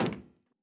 added stepping sounds
LowMetal_Mono_04.wav